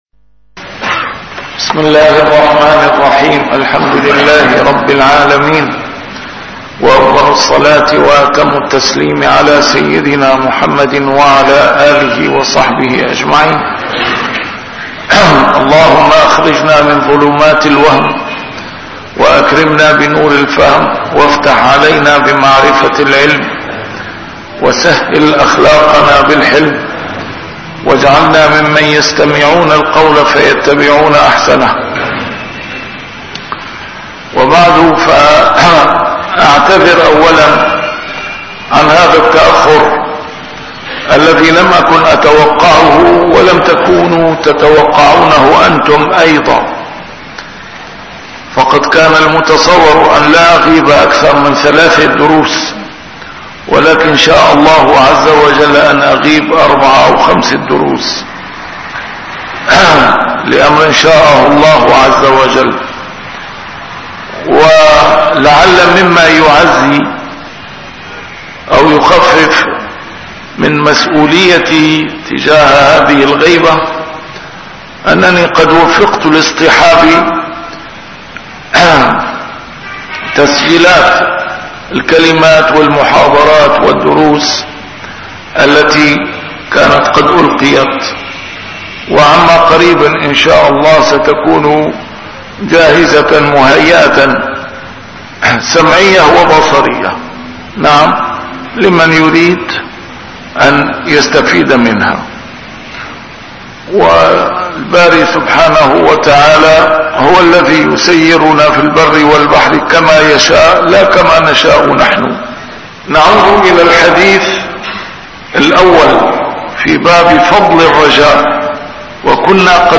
A MARTYR SCHOLAR: IMAM MUHAMMAD SAEED RAMADAN AL-BOUTI - الدروس العلمية - شرح كتاب رياض الصالحين - 497- شرح رياض الصالحين: فضل الرجاء